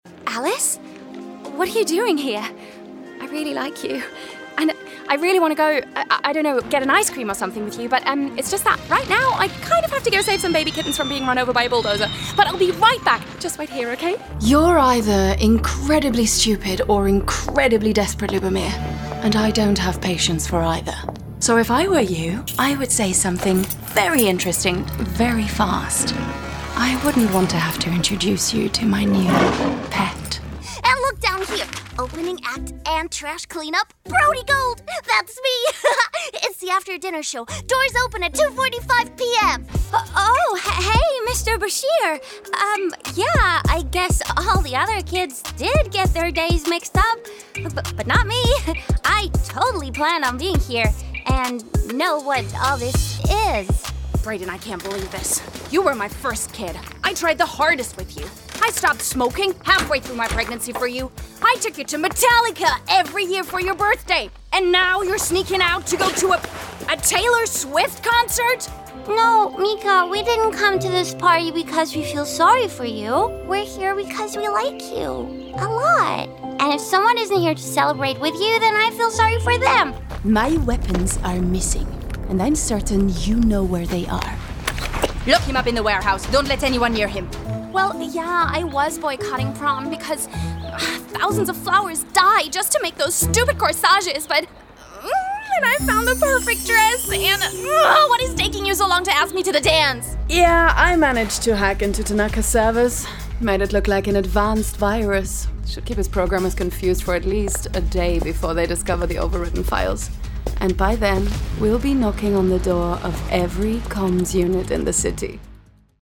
Female
Bright, Character, Cheeky, Children, Confident, Cool, Friendly, Natural, Soft, Versatile, Young, Engaging, Warm
Her voice is relatable, contemporary and youthful with a warm and textured sound.
Microphone: Sennheiser MKH 416, Rode NT1-A